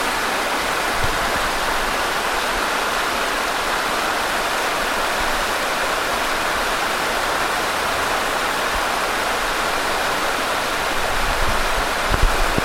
river-1.mp3